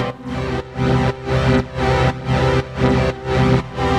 GnS_Pad-MiscA1:4_120-C.wav